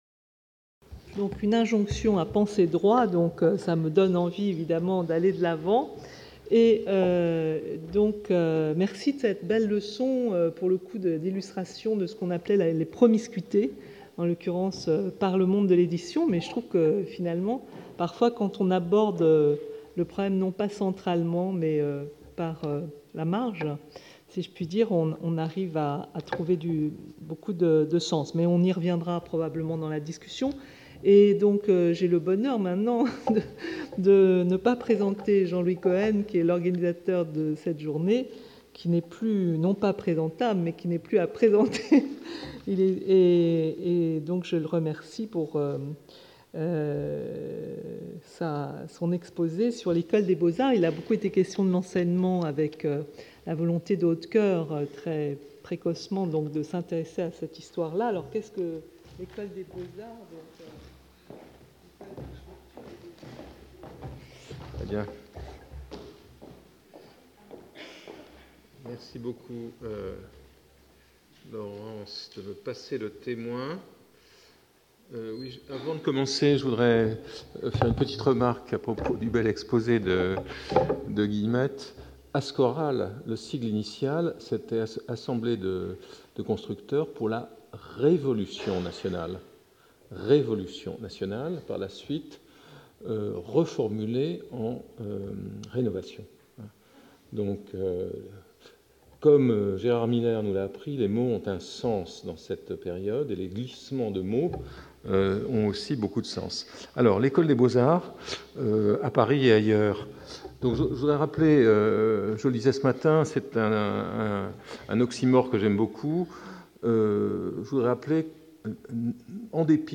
Dans le même temps, la création d’une Section des hautes études dessine une nouvelle stratégie pour former les élites de la profession. Intervenant(s) Jean-Louis Cohen Architecte, Professeur à l'Université de New York et au Collège de France
Colloque